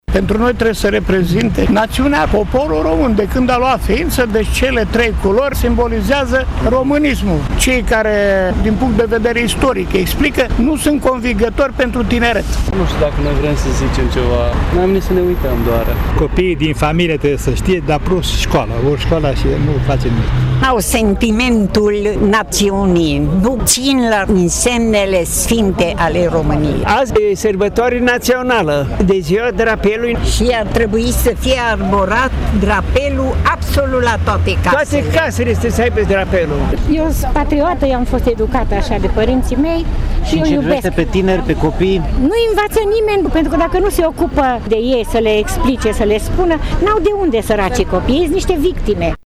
La cereremonia de azi de la Tîrgu Mureș au fost destul de puține persoane prezente, majoritatea persoane în vârstă.
Cei care au asistat, însă, s-au exprimat decis în favoarea unui patriotism sănătos, indiferent de starea materială a românilor sau de faptul că mulți pleacă din țară: